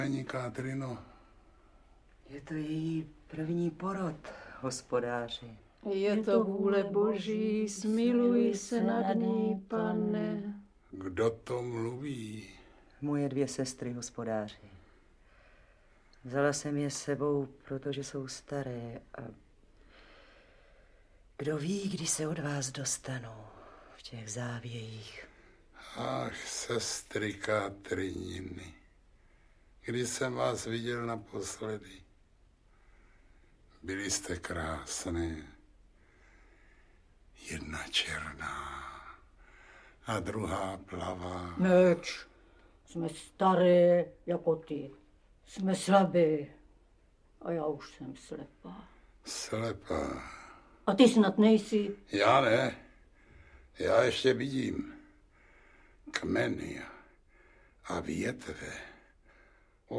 Audiobook
Audiobooks » Short Stories
Read: Antonie Hegerlíková